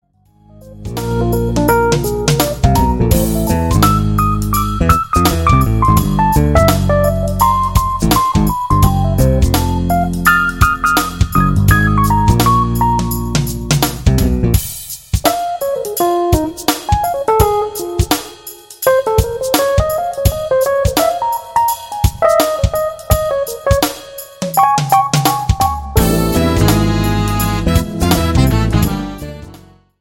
JAZZ  (02.14)